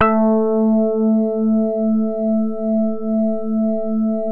JAZZ MID  A2.wav